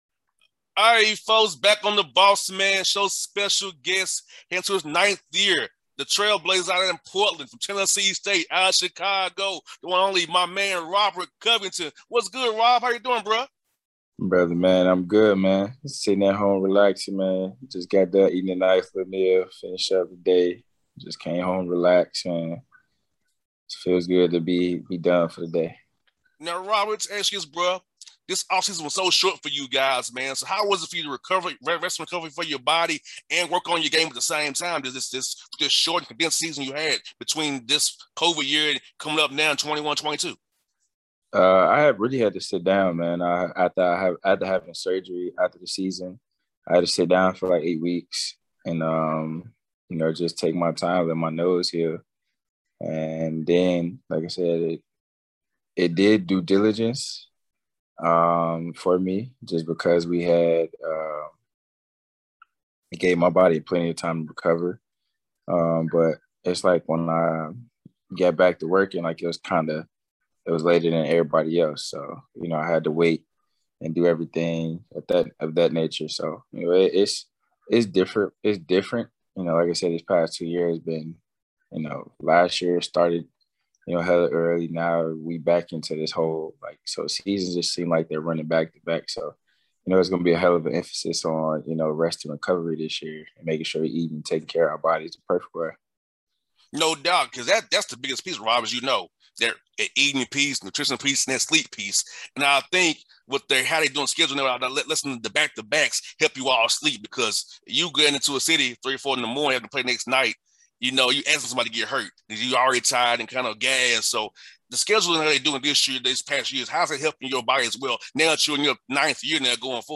Robert Covington Interview